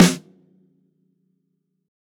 TUNA_SNARE_2.wav